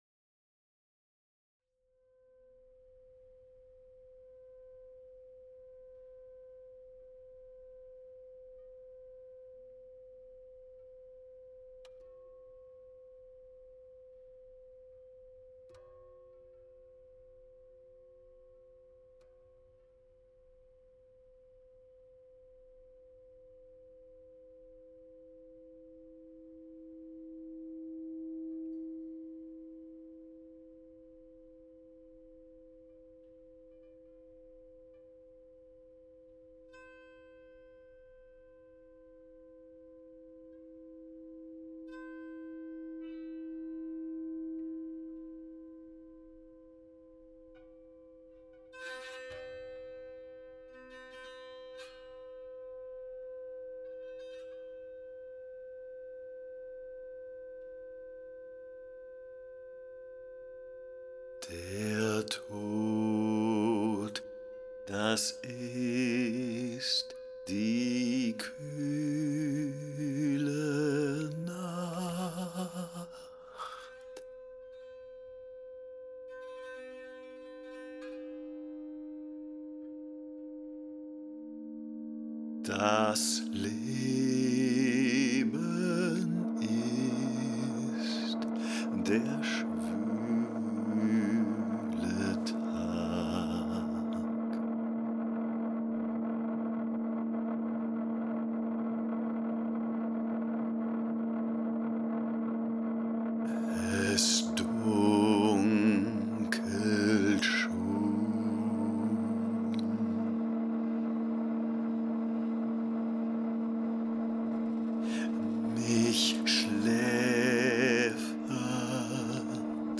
piano
chant